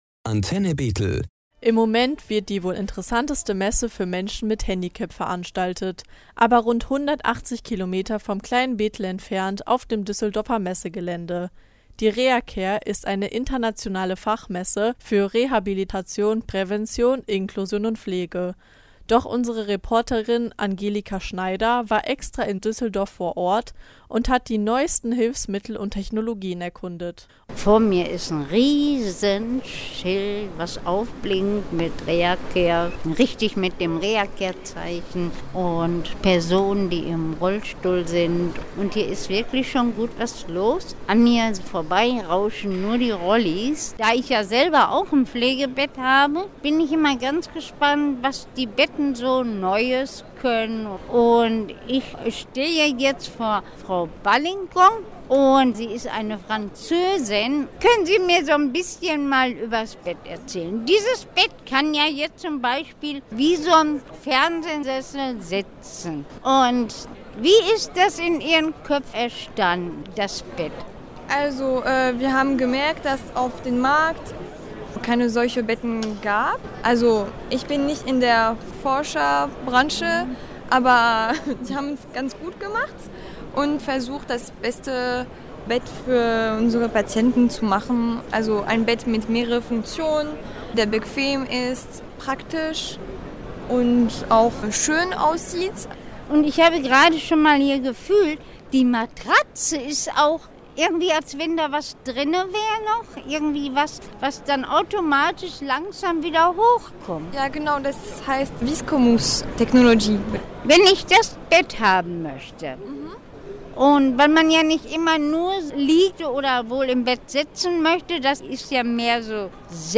Auf der Messe werden Innovationen, Produkte und Dienstleistungen vorgestellt, die das Ziel haben, die Teilhabe zu ermöglichen und physische sowie mentale Barrieren abzubauen. Antenne Bethel hat diese Messe für Sie besucht: